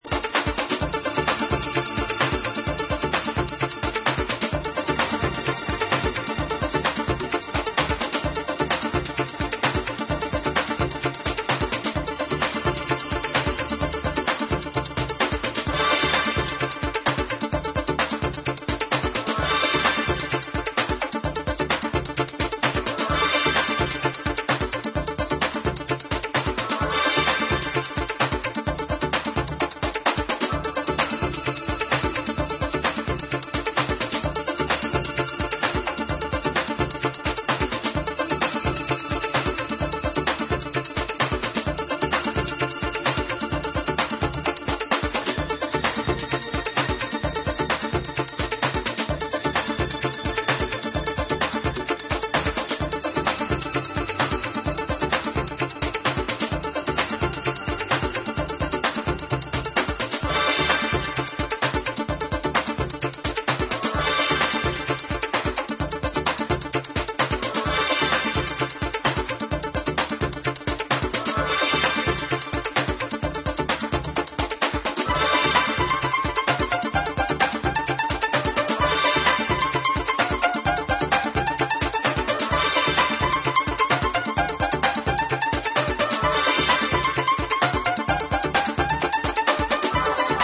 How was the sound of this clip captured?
Recut and remastered!